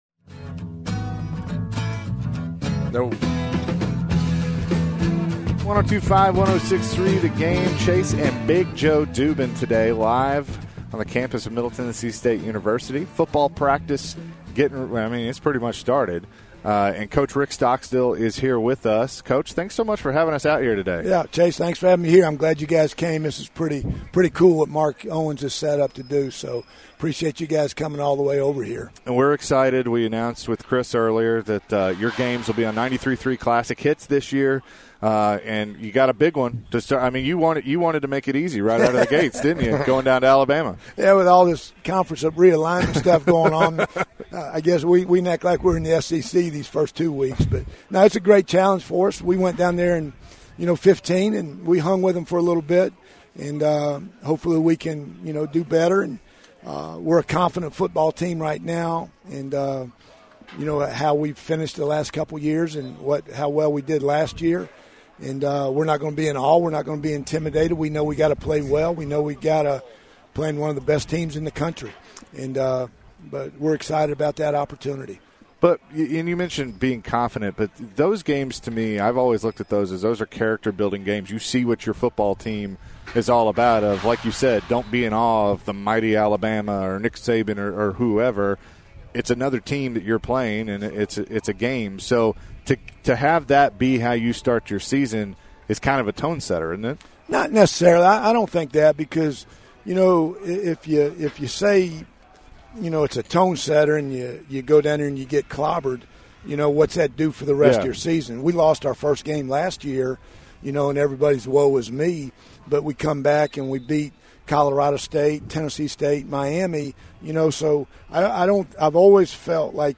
Interview
live at MTSU football practice